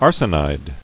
[ar·se·nide]